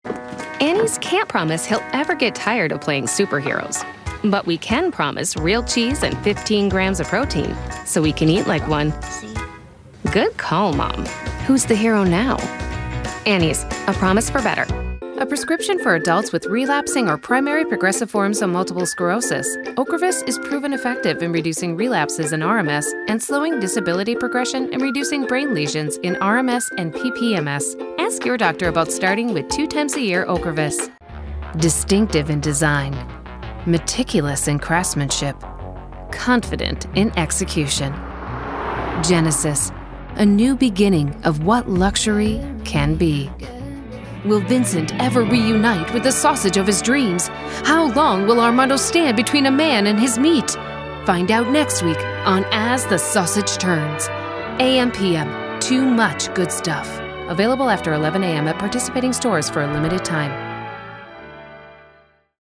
Female
English (North American)
Yng Adult (18-29), Adult (30-50)
Commercial Demo